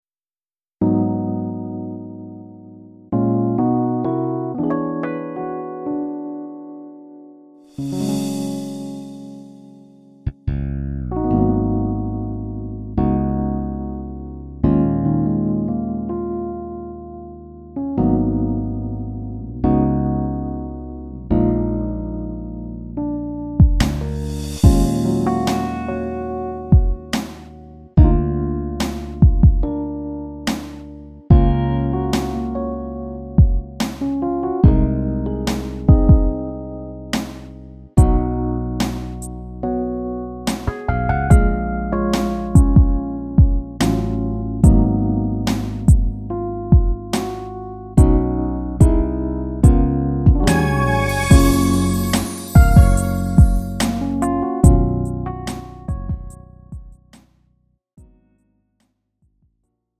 음정 -1키 3:39
장르 가요 구분 Lite MR
Lite MR은 저렴한 가격에 간단한 연습이나 취미용으로 활용할 수 있는 가벼운 반주입니다.